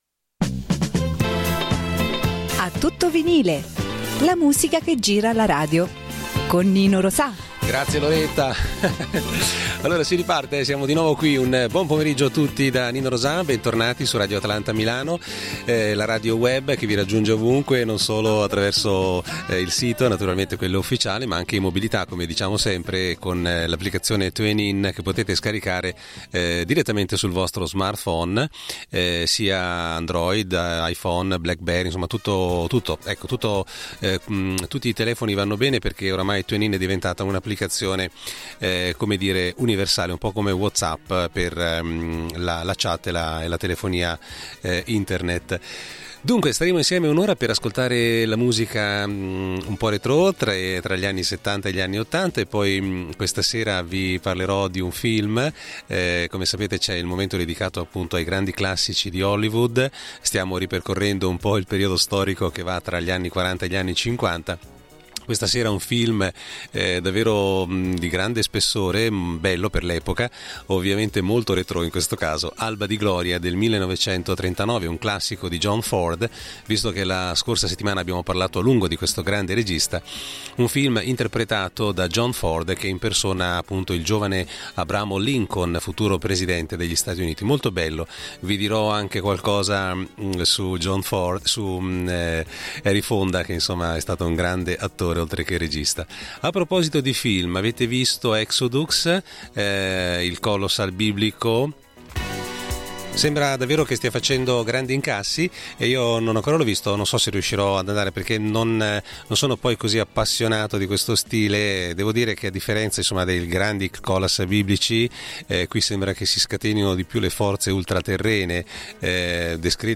Musica “a tutto Vinile” ma anche notizie sugli artisti senza tempo della black dance, considerati i primi della classe.
Un’ora un po’ retro… ma non troppo